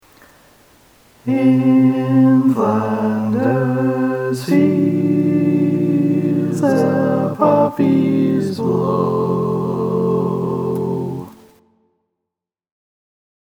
Key written in: F Major
How many parts: 4
Type: Other mixed
Nice gentle 4-part suspension-chord tag
All Parts mix: